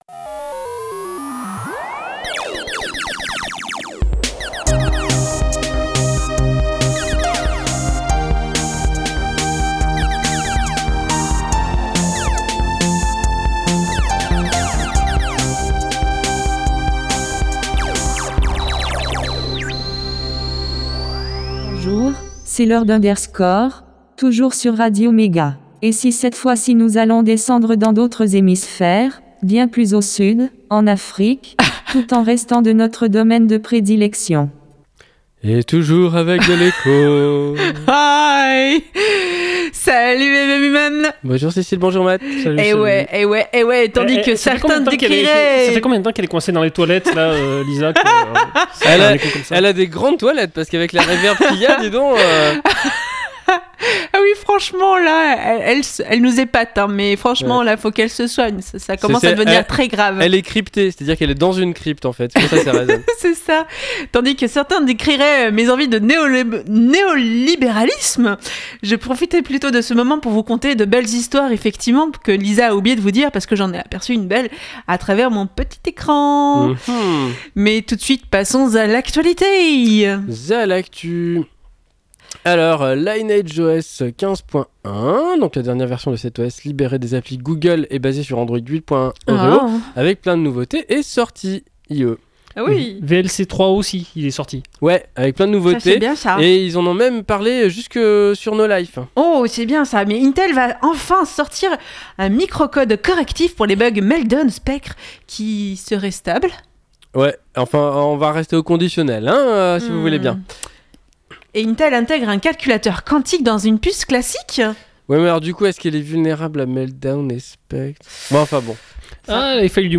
Chappie De l'actu, une pause chiptune, un sujet, l'agenda, et astrologeek !